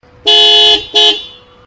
horn.ogg